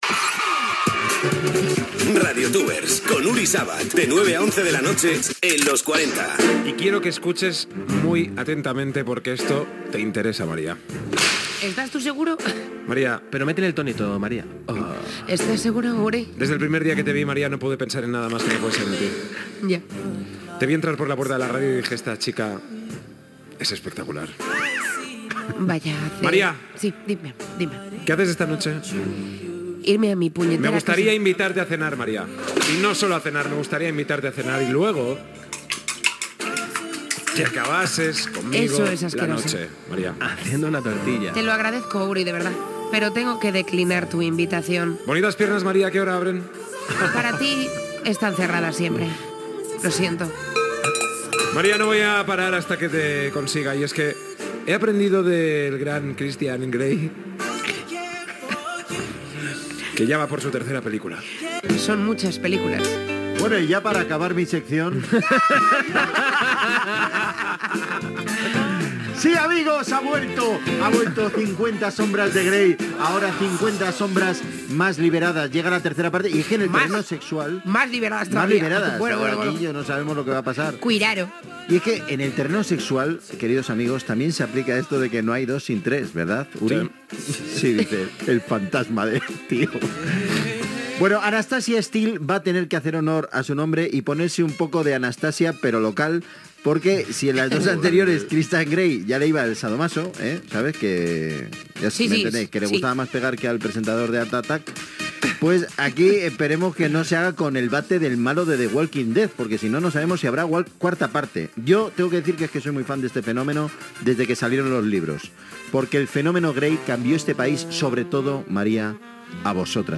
Indicatiu del programa, diàleg sobre el llibre i les pel·lícules de "Cinquanta ombres d'en Grey"
Entreteniment
FM